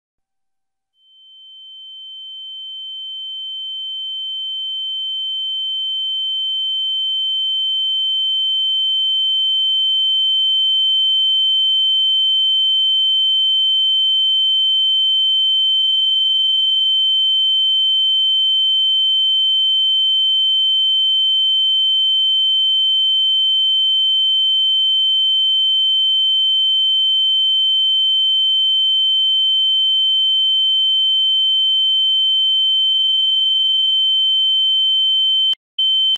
Ear Ringing Unbearable Sound
meme